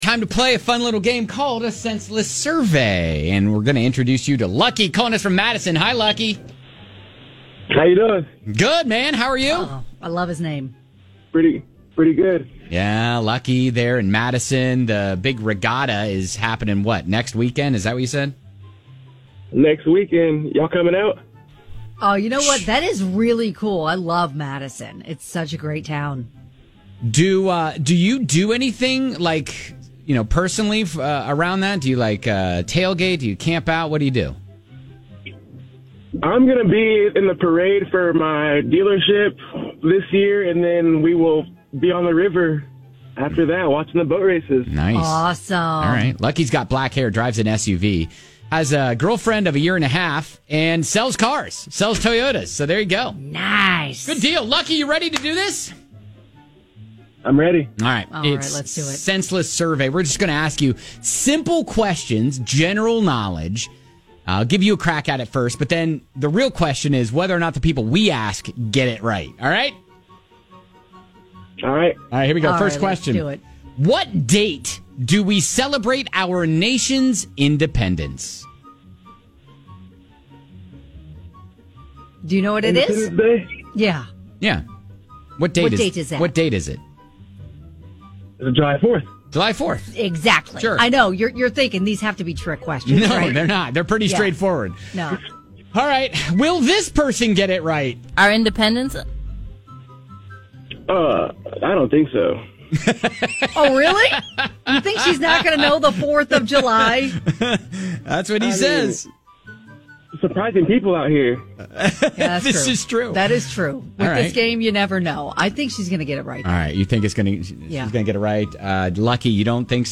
All you have to do is guess whether the people being asked these random trivia questions will get it right or not!